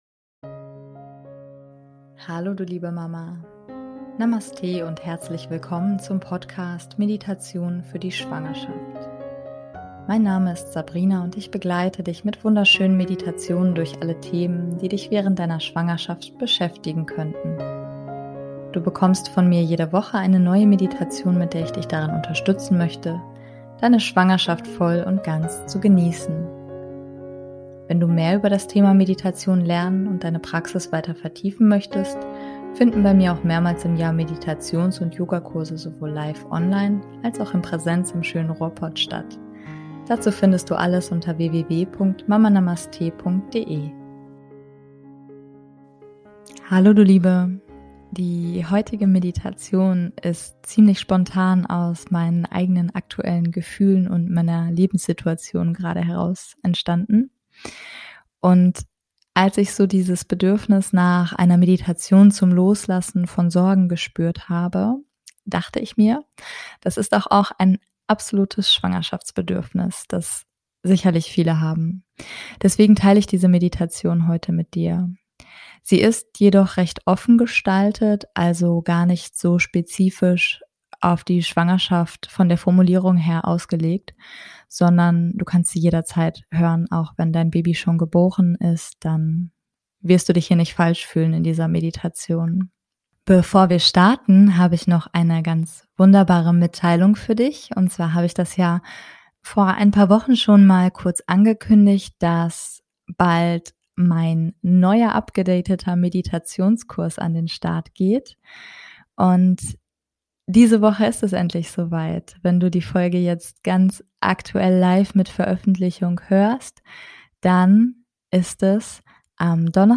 #083 - Meditation zum Loslassen von Sorgen [für Schwangere, Mamas und Alle] ~ Meditationen für die Schwangerschaft und Geburt - mama.namaste Podcast